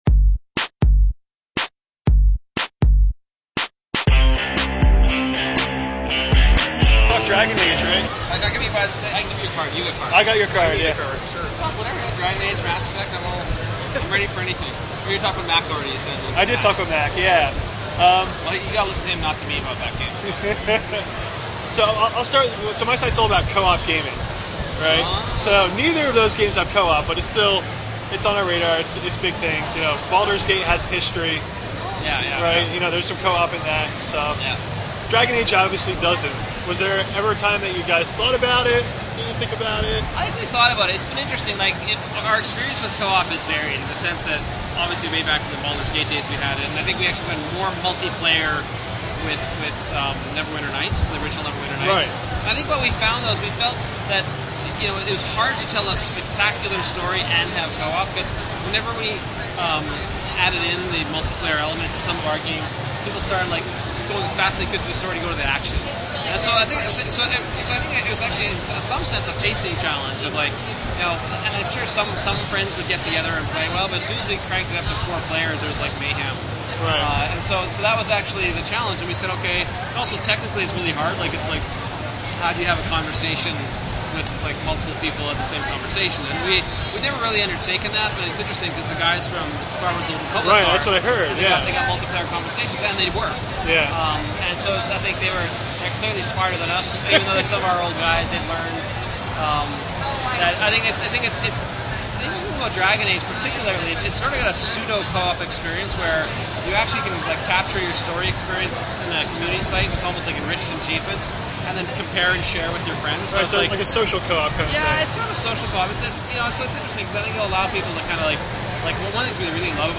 News - Co-Optimus Interviews Dr Greg Zeschuk on Dragon Age and Beautiful Tunnels
We chatted with Bioware's co-president at PAX about the game's design, standout features, a beautiful tunnel and of course - the lack of co-op.